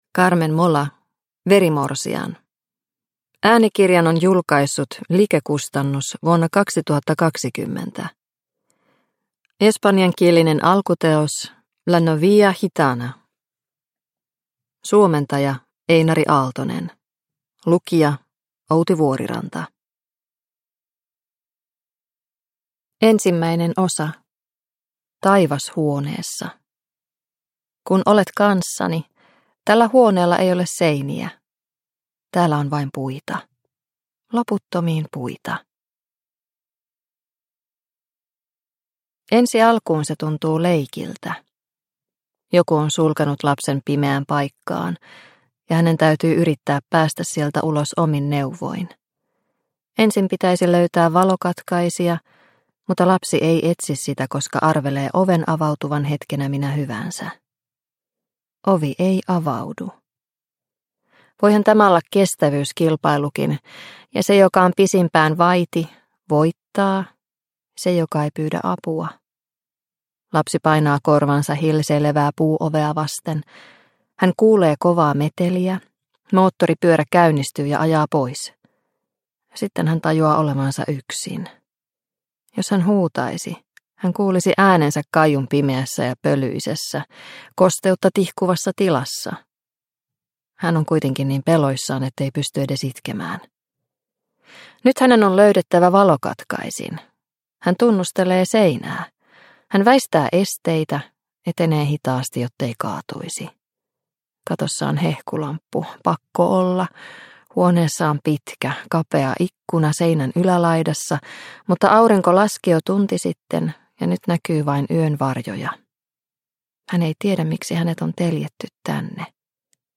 Verimorsian – Ljudbok – Laddas ner